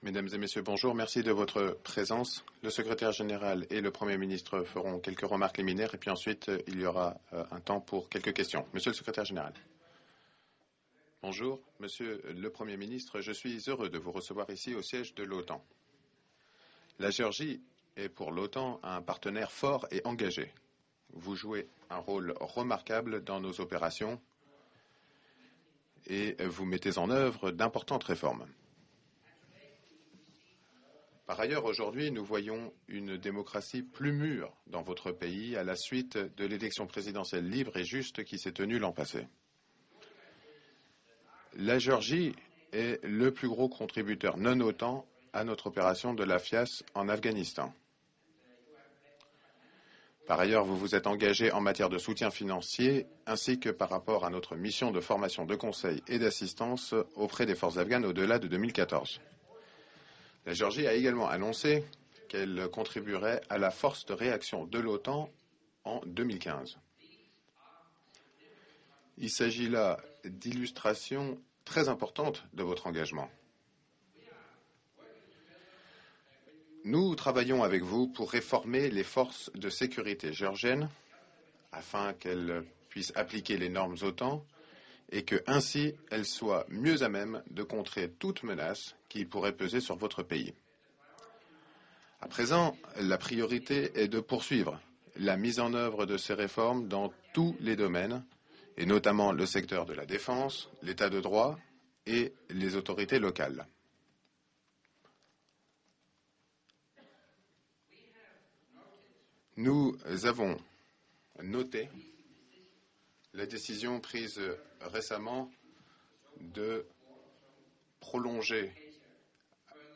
ORIG - Joint press point with NATO Secretary General Anders Fogh Rasmussen and the Prime Minister of Georgia, Irakli Garibashvili 05 Feb. 2014 | download mp3 FRENCH - Joint press point with NATO Secretary General Anders Fogh Rasmussen and the Prime Minister of Georgia, Irakli Garibashvili 05 Feb. 2014 | download mp3